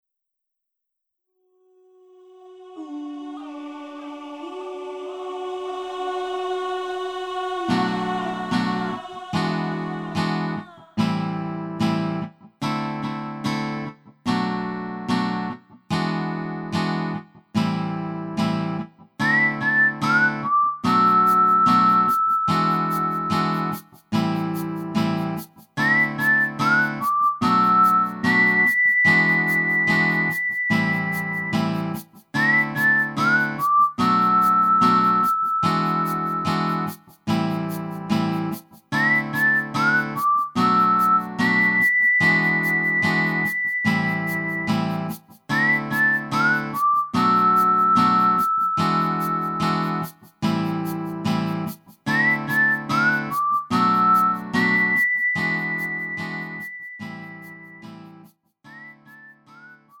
미리듣기
음정 원키 6:26
장르 가요 구분